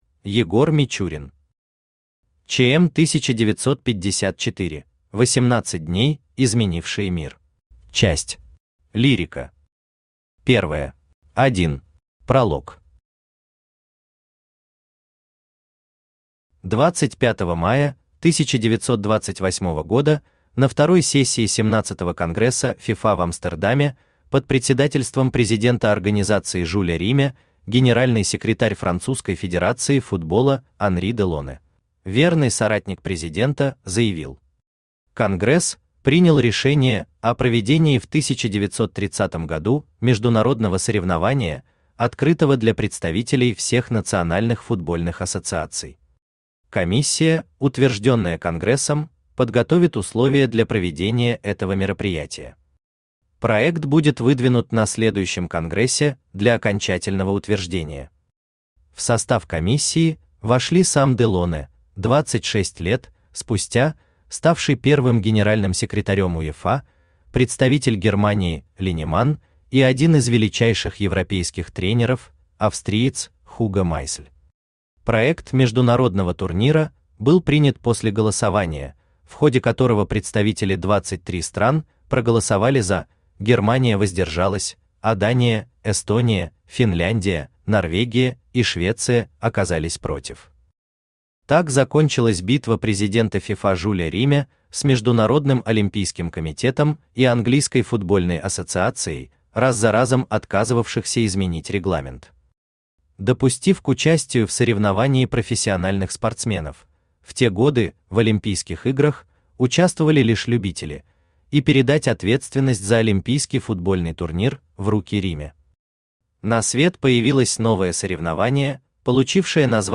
Аудиокнига ЧМ-1954: 18 дней, изменившие мир | Библиотека аудиокниг
Aудиокнига ЧМ-1954: 18 дней, изменившие мир Автор Егор Мичурин Читает аудиокнигу Авточтец ЛитРес.